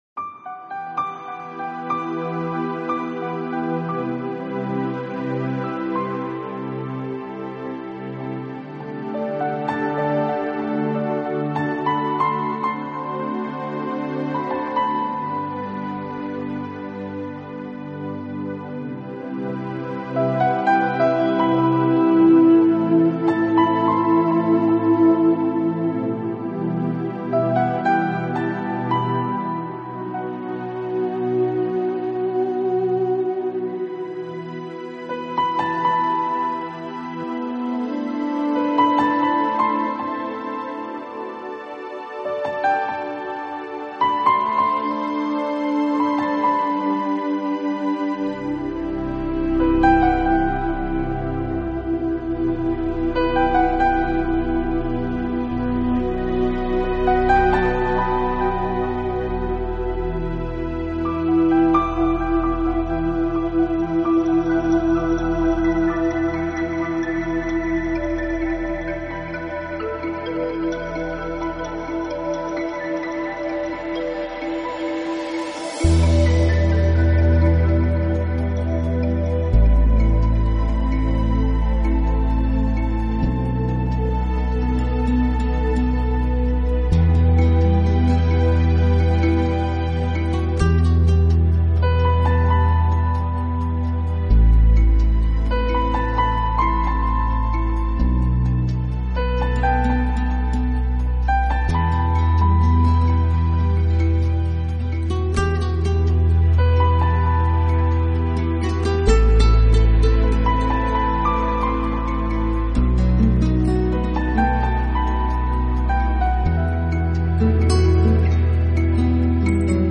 音乐类型：New Age / Piano Solo